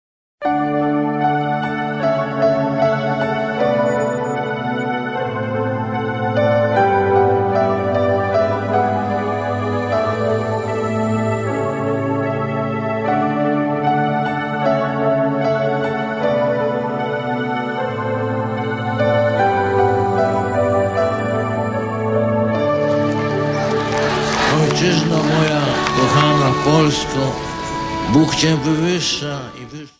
Nauczyliśmy się naszej Ojczyzny - wypowiedzi Ojca Świętego do rodaków
We've Learned Our Homeland - The Holy Father's Statements to His Countrymen 1978—2002